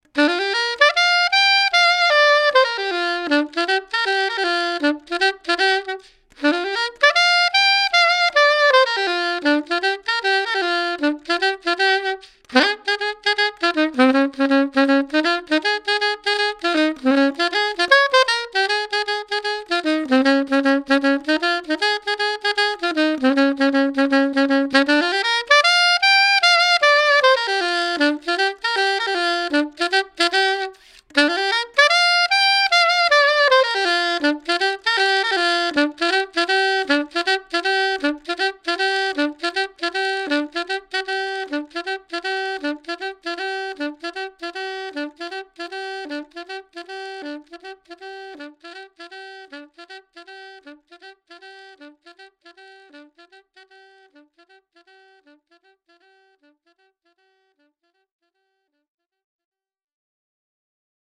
Recorded live at home in Manhattan January 31, 2015
soprano saxophone